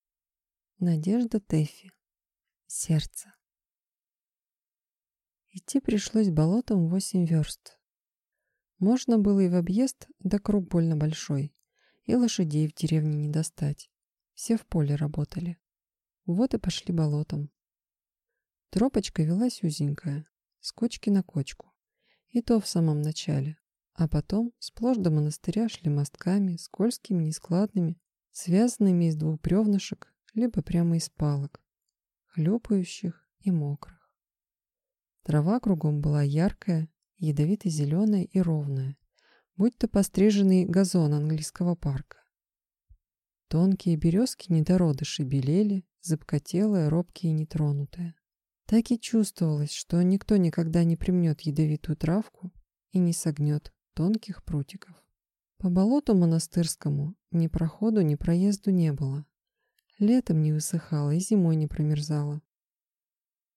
Аудиокнига Сердце | Библиотека аудиокниг